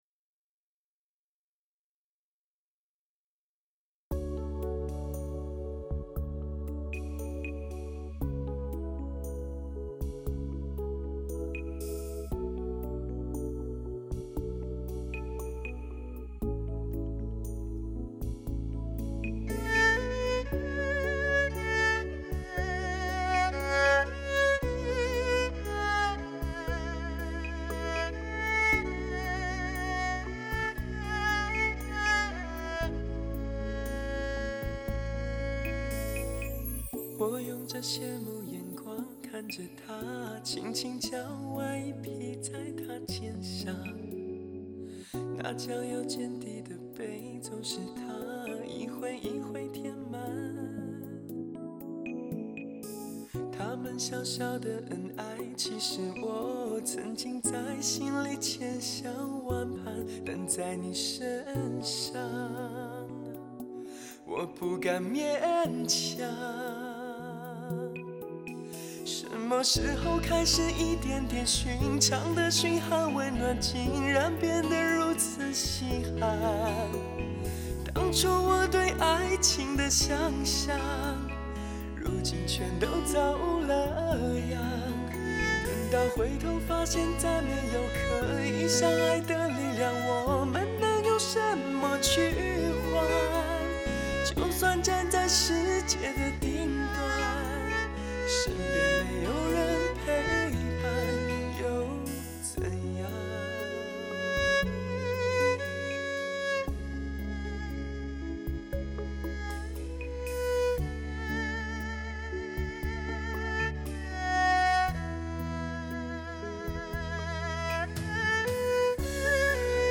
6.1声道环绕你整个磁埸指定专业机器播放